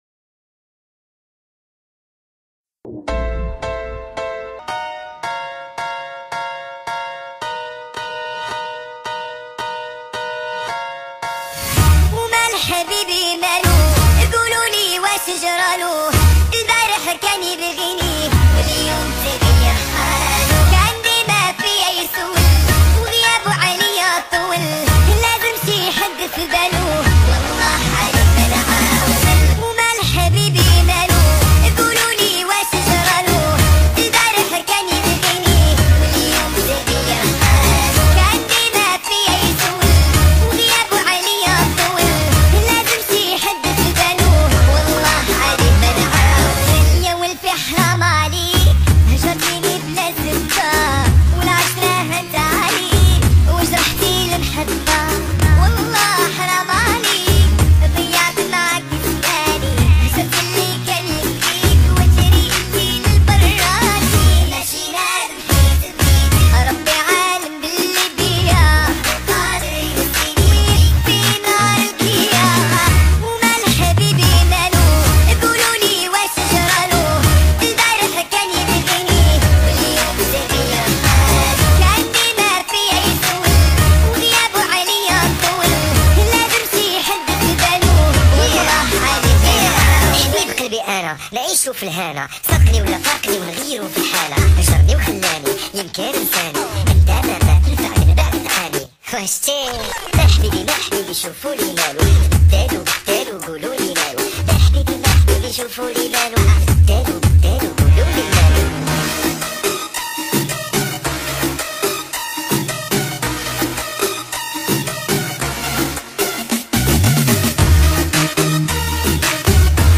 با صدای بچه